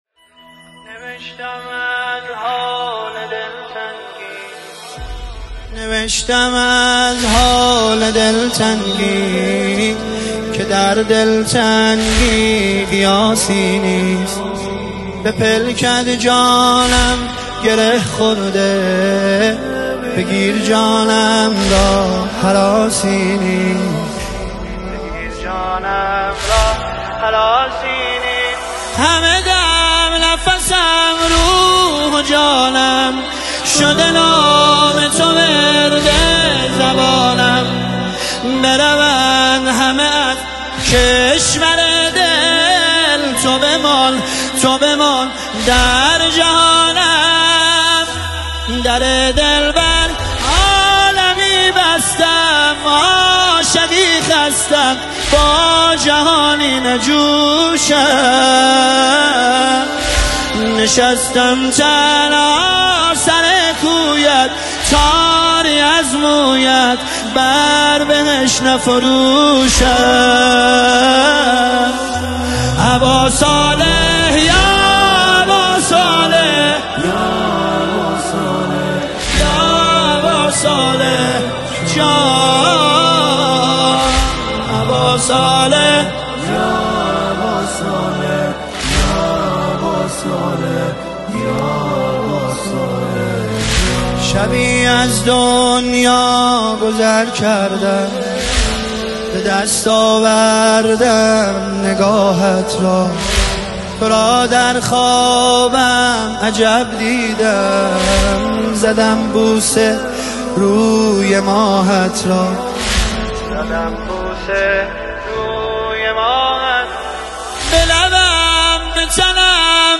نماهنگ و مناجات مهدوی
با نوای دلنشین